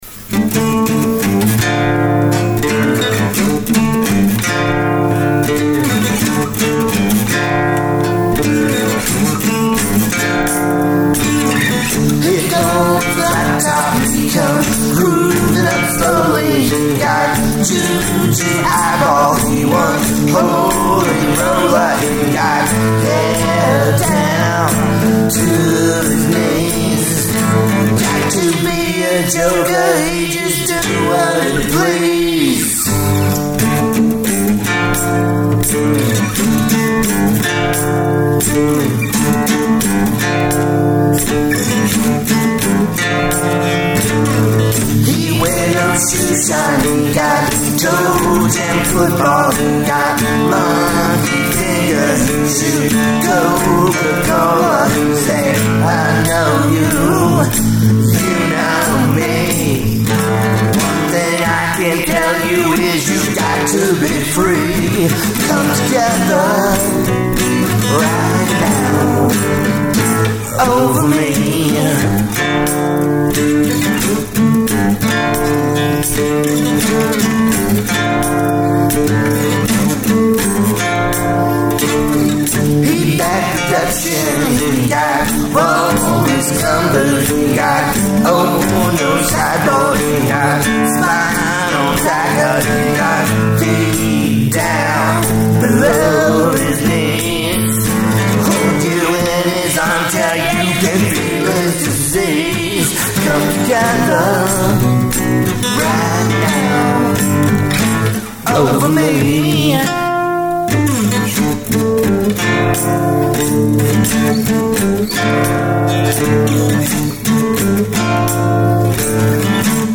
Love the added female singer